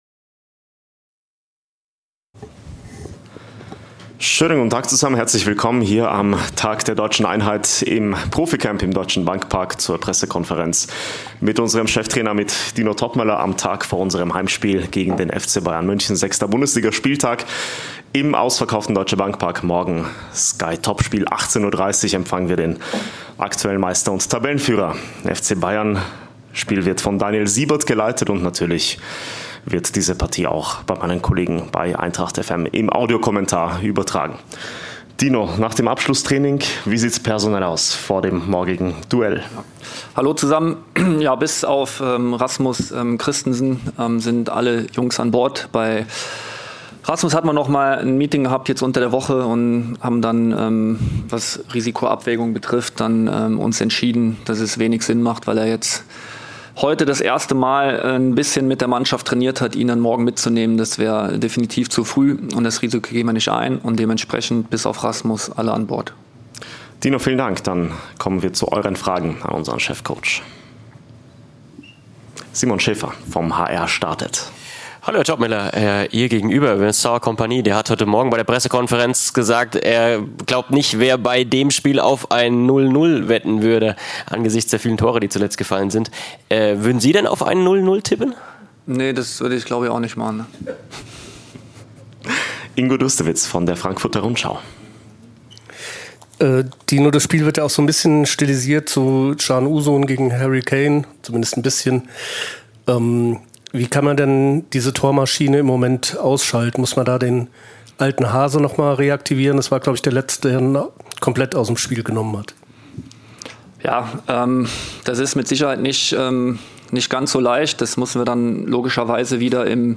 Cheftrainer Dino Toppmöller spricht im Vorfeld des Bundesliga-Heimspiels gegen den FC Bayern München mit den Medienvertretern.